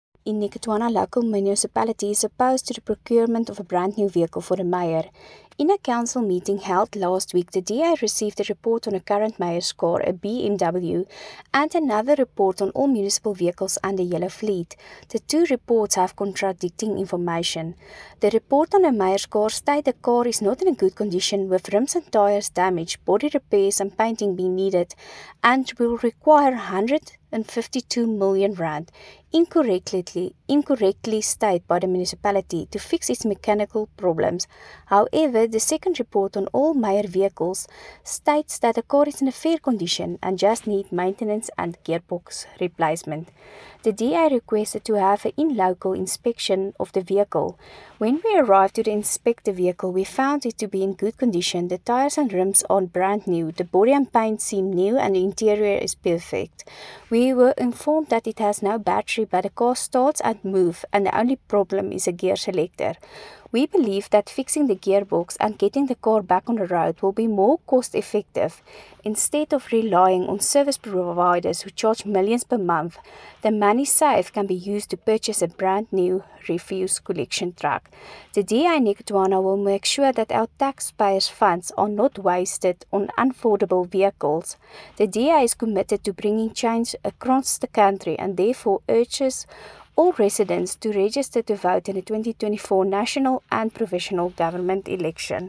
Afrikaans soundbites by Cllr Anelia Smit.